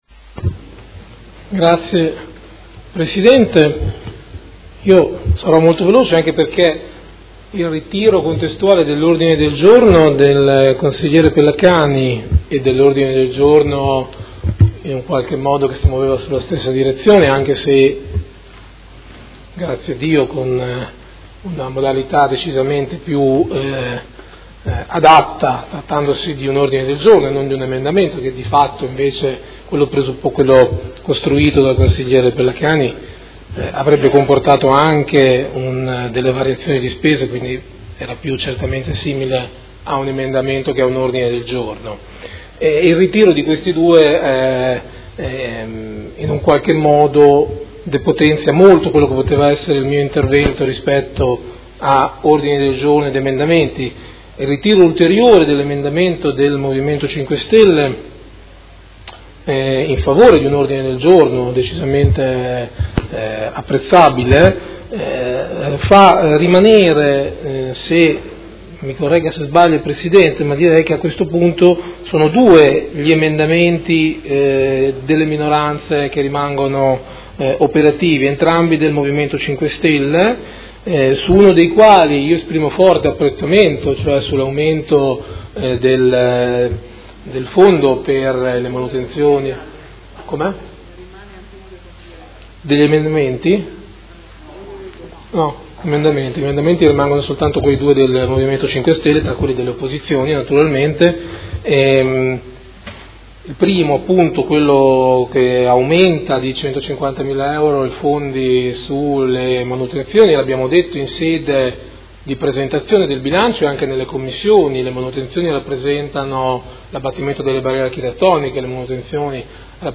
Andrea Bosi — Sito Audio Consiglio Comunale
Seduta del 22/02/2018 Bilancio. Replica a dibattito sulle delibere, emendamenti e ordini del giorno.